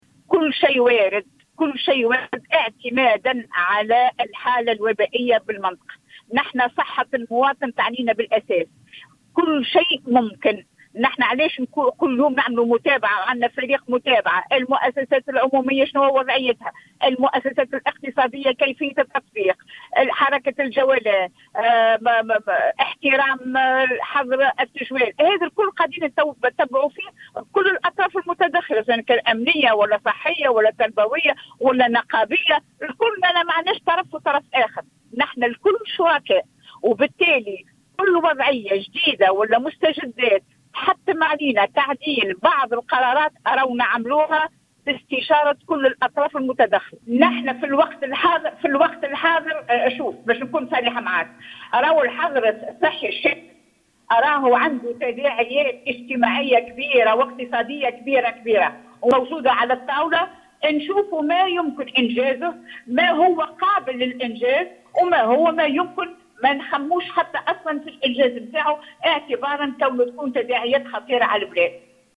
قالت والية سوسة رجاء الطرابلسي اليوم الجمعة 09 أكتوبر 2020، في تصريح للجوهرة أف أم، في ردها على سؤال حول إمكانية فرض حجر صحي شامل في الجهة، إن كل السيناريوهات تبقى واردة، تبعا لتطور الوضع الوبائي في ولاية سوسة.